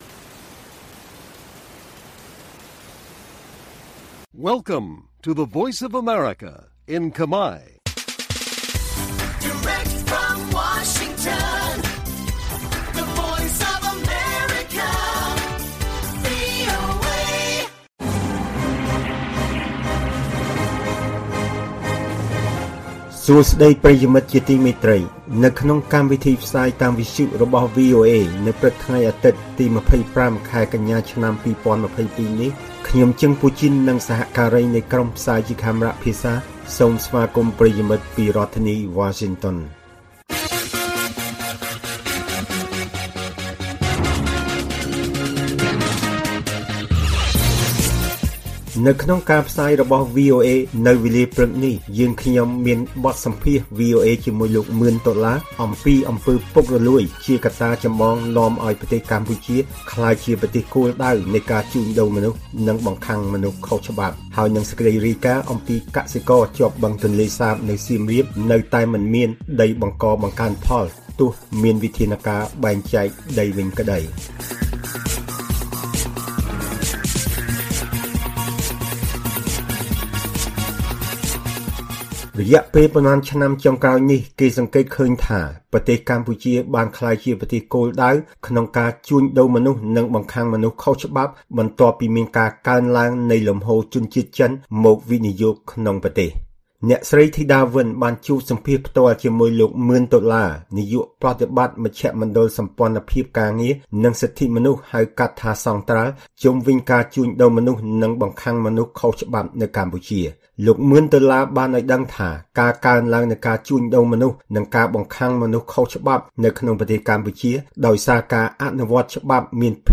ព័ត៌មានពេលព្រឹក២៥ កញ្ញា៖ បទសម្ភាសន៍អំពីអំពើពុករលួយជាកក្តាចម្បងនាំឱ្យកម្ពុជាក្លាយជាប្រទេសគោលដៅនៃការជួញដូរមនុស្ស